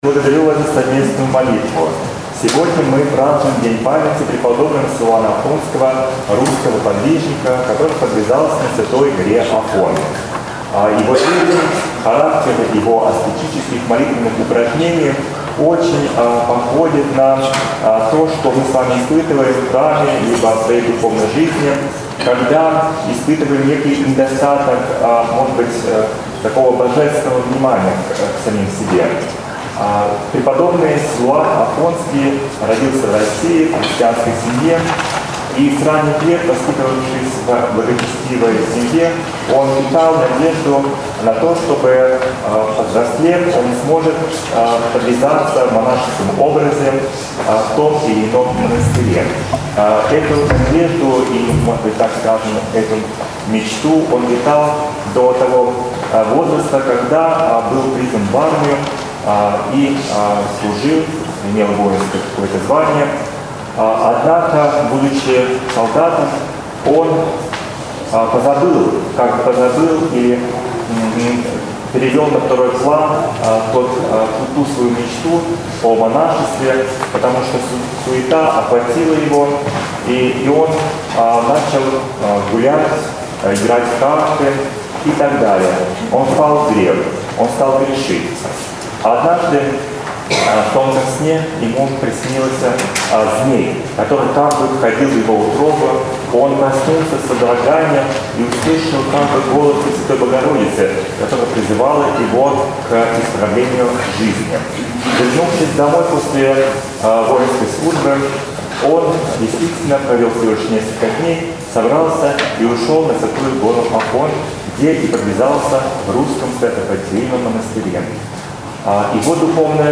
Седмица 18-я по Пятидесятнице. Прп. Силуана Афонского. Проповедь